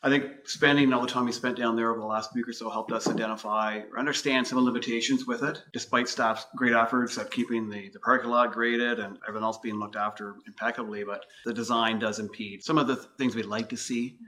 Mayor Shawn Pankow said the Old Home Week festivities brought a spotlight to the existing limitations of the park.